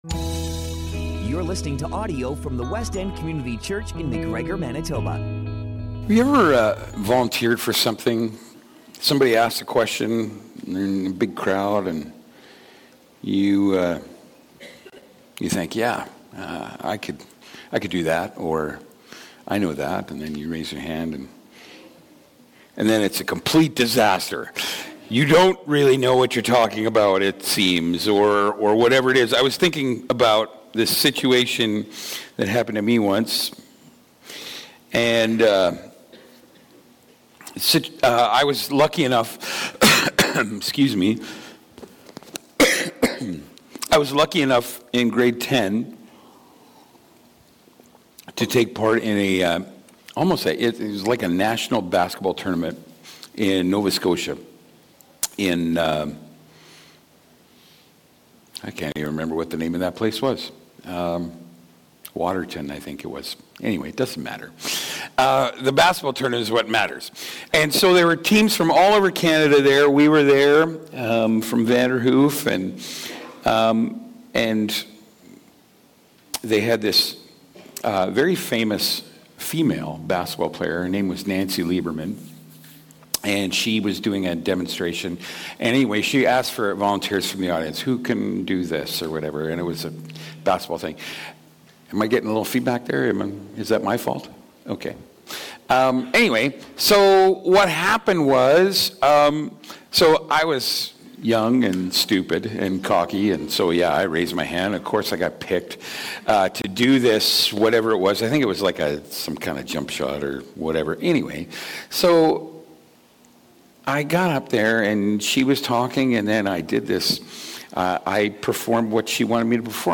Sermons - Westend Community Church